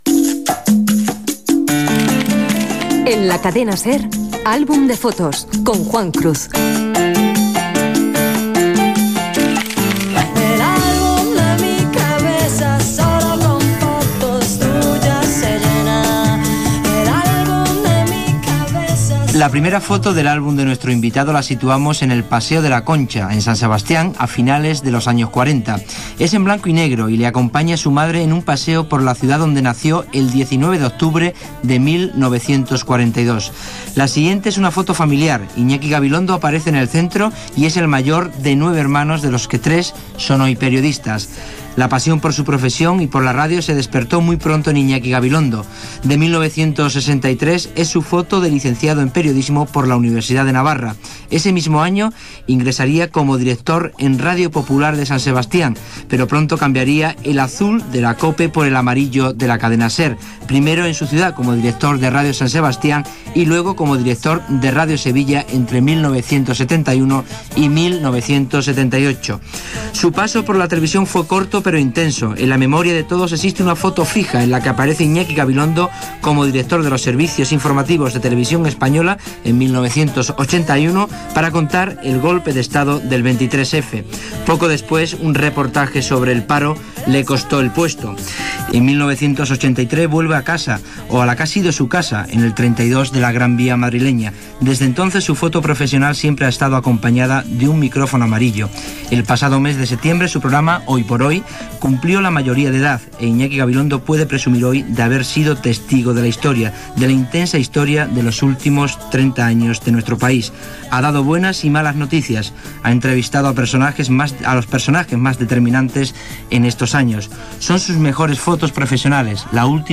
Careta, introducció biogràfica i entrevista a Iñaki Gabilondo
Divulgació